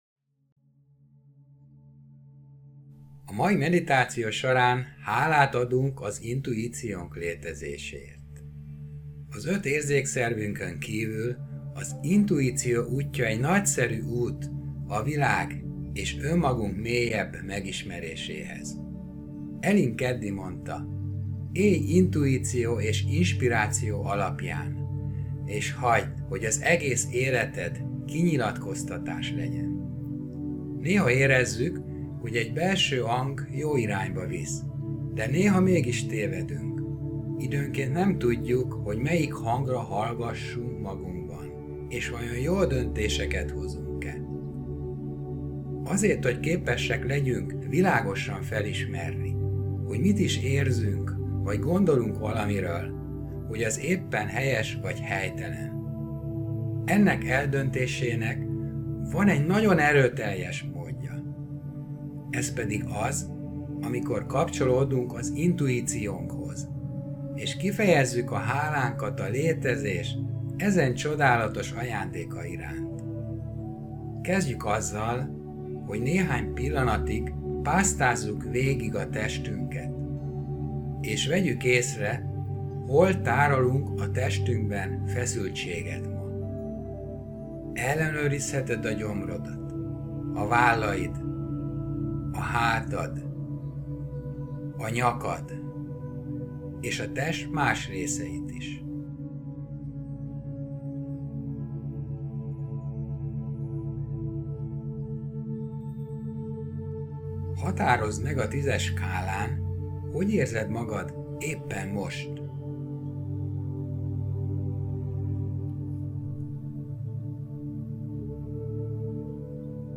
Ez a meditáció nem csupán az intuitív étkezés elsajátítását segíti, de segíti az intuitív megérzések felismerését az életed minden területén, legyen az akár a kapcsolatok, a hivatás, a pénzkereset területe vagy bármi más.